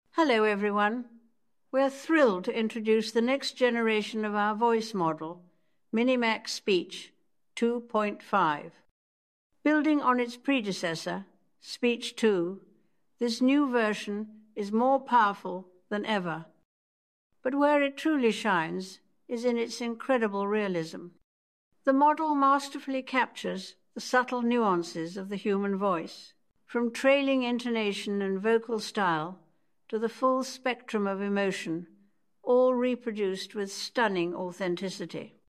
智东西8月7日报道，今天，MiniMax推出新一代语音生成模型Speech 2.5
用英国女王的经典发音来介绍最新的Speech 2.5会是什么样？
从停顿、节奏、到发音处理，模型生成的语音保持了纯正的“女王腔”。